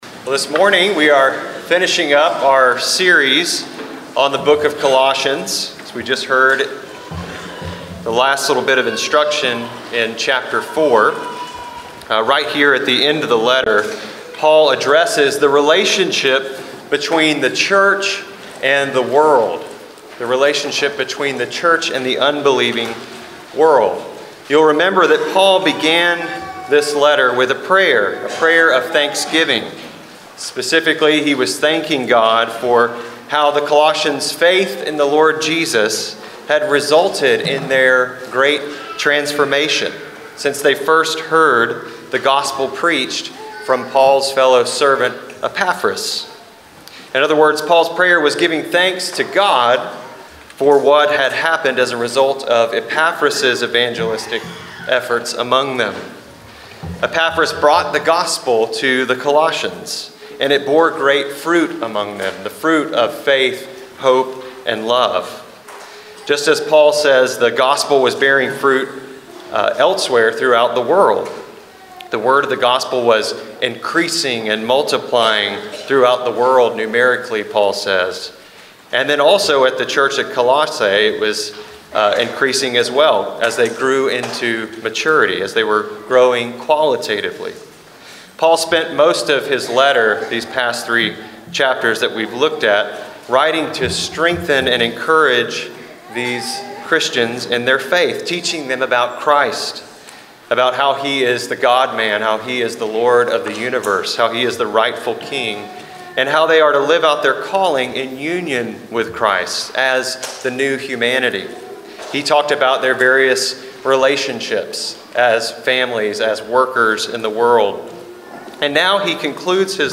sundaysermon.12.1.24.mp3